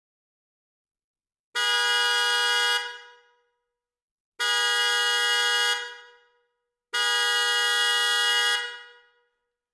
Fisa luchthoorn Sport FXS2 1T H+L 12V112dB120W500Hz high tone400Hz low tone